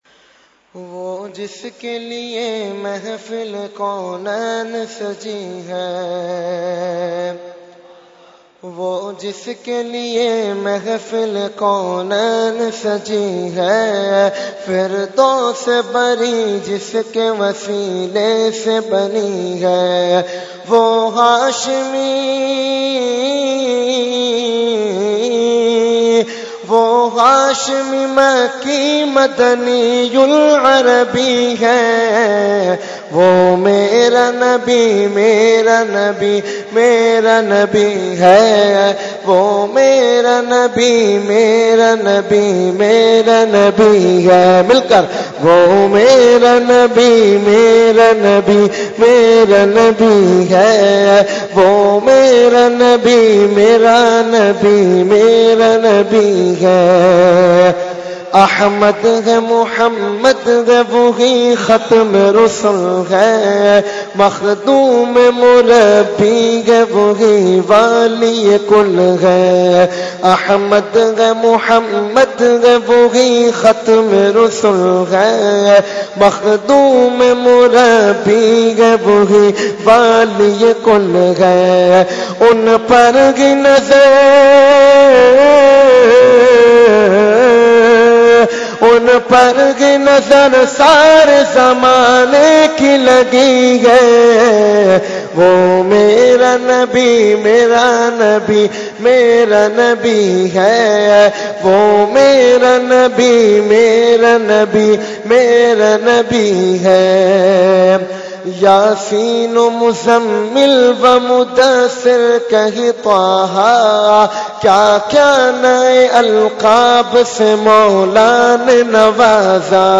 Category : Naat | Language : UrduEvent : Jashne Subah Baharan 2016